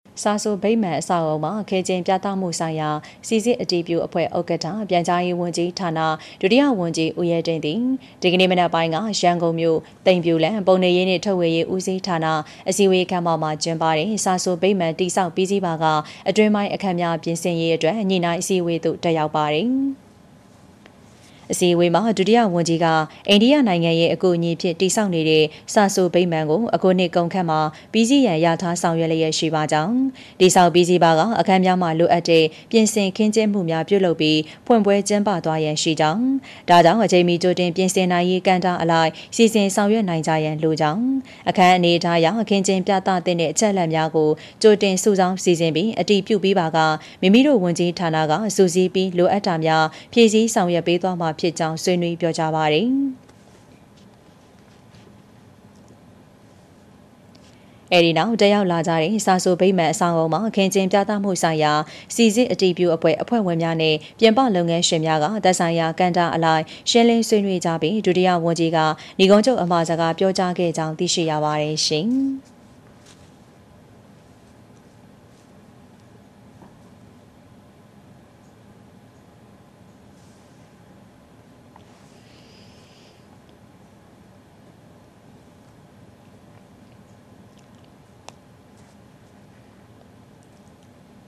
ပြန်ကြားရေးဝန်ကြီးဌာန၊ ဒုတိယဝန်ကြီး ဦးရဲတင့် စာဆိုဗိမာန်အဆောက်အဦတွင် စာဆိုဗိမာန် တည်ဆောက်ပြီးစီးပါက အတွင်းပိုင်းအခန်းများ ပြင်ဆင်ရေး ညှိနှိုင်းအစည်းအဝေးသို့ တက်ရောက်